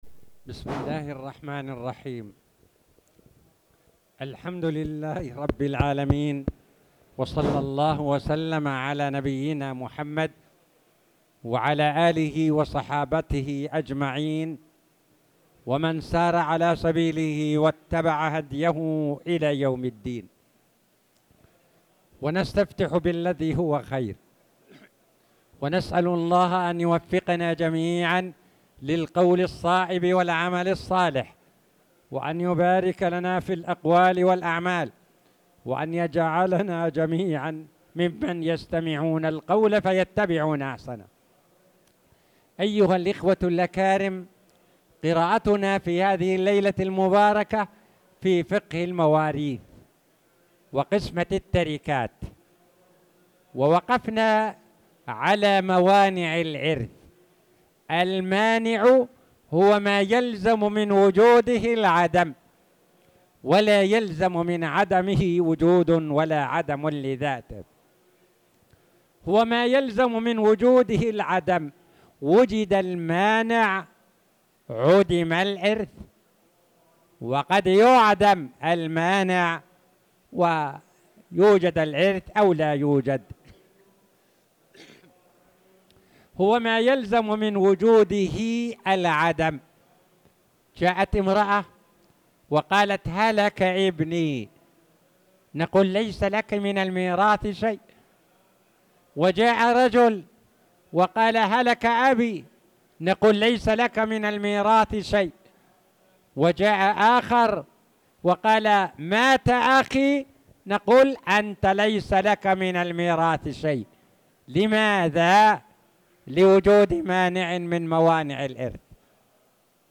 تاريخ النشر ١٣ ذو القعدة ١٤٣٧ هـ المكان: المسجد الحرام الشيخ